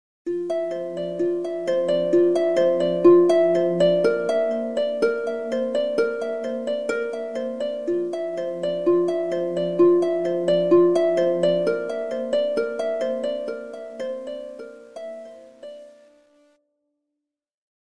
• Качество: 128, Stereo
Стандартный рингтон